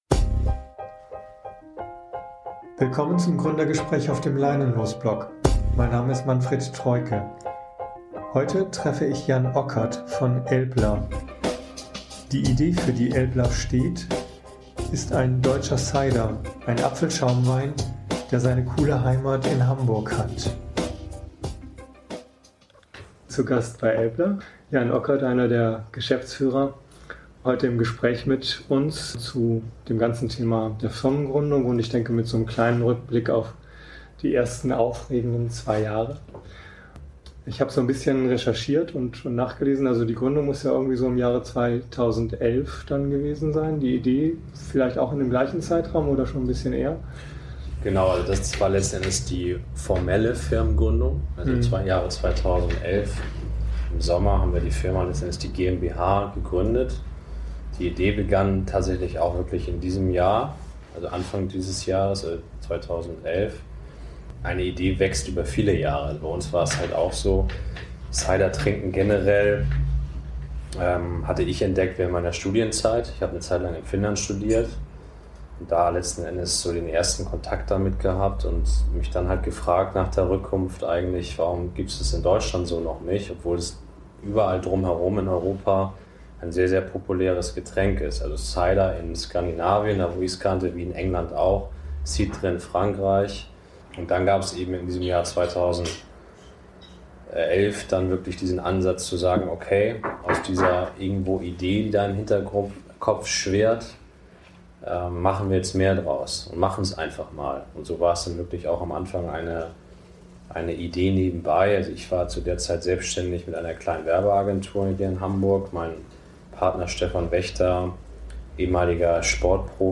Interview-Download
Hintergund-Musik bei Intro und Abspann